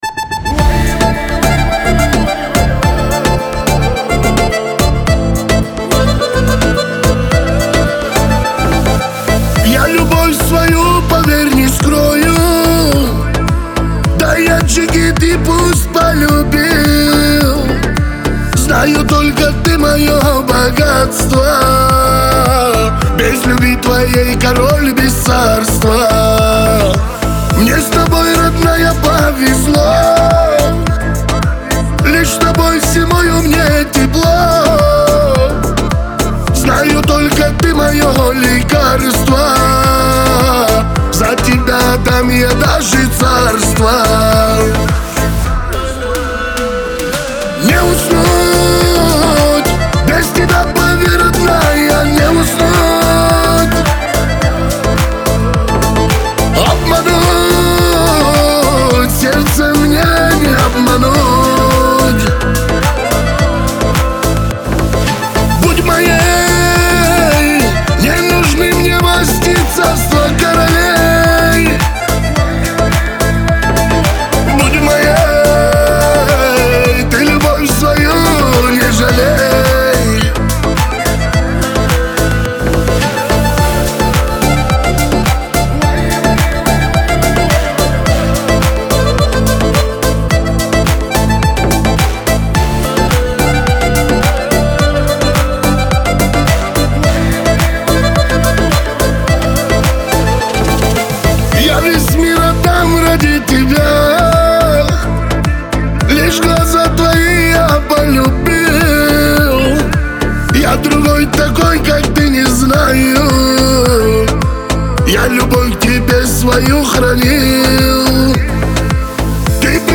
Лирика , Кавказ – поп